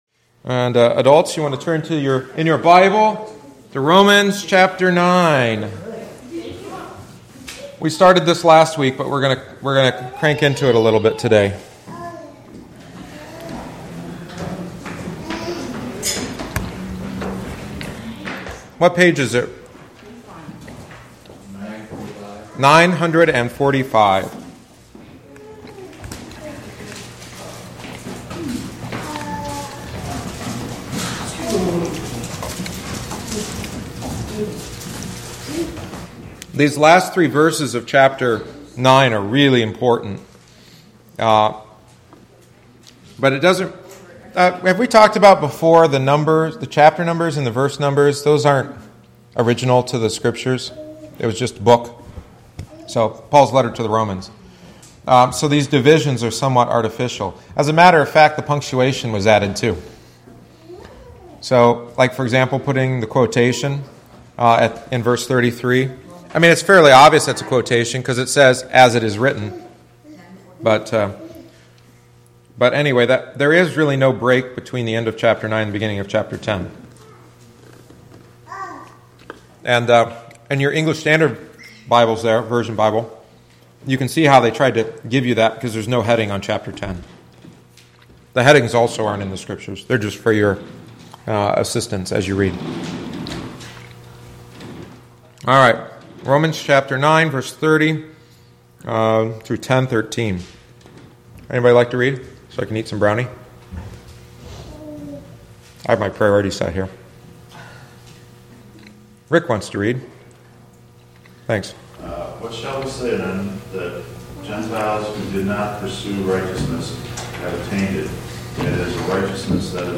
The following is the twenty-fourth week’s lesson.